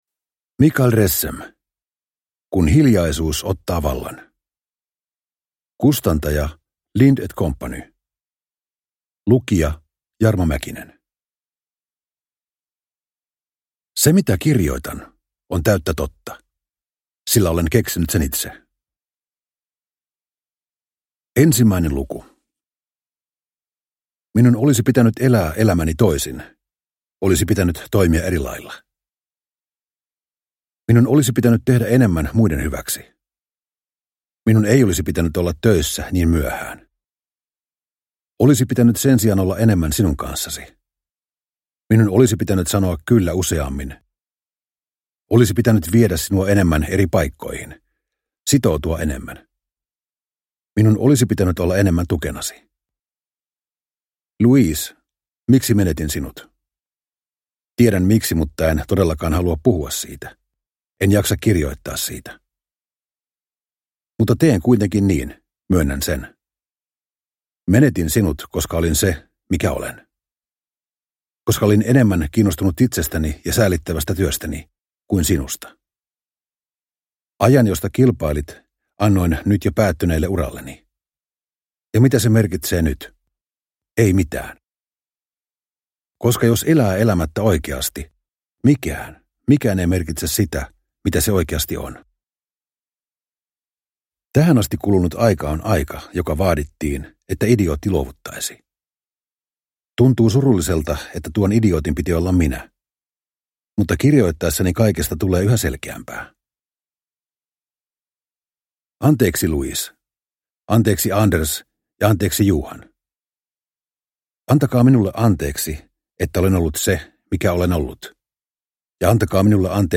Kun hiljaisuus ottaa vallan – Ljudbok – Laddas ner
Uppläsare: Jarmo Mäkinen